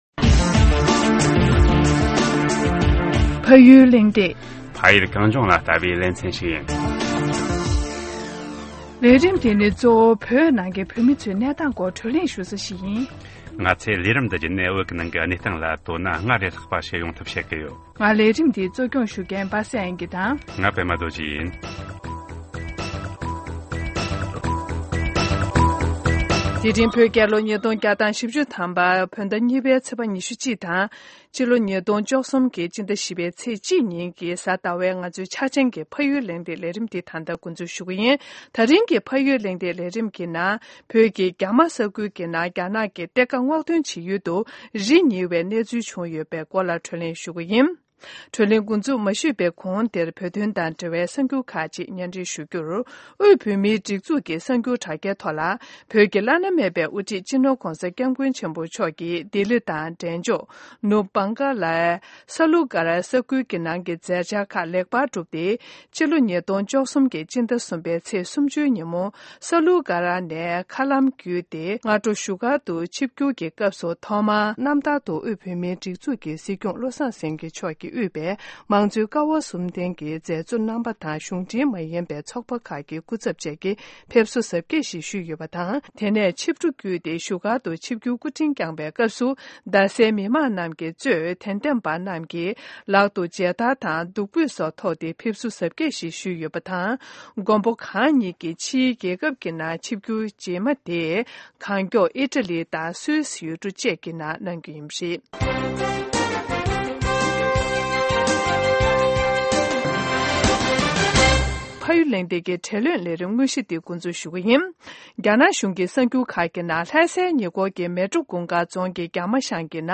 བགྲོ་གླེང་ཞུས་པ་ཞིག་གསན་རོགས་གནང་།